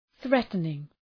Προφορά
{‘ɵretənıŋ}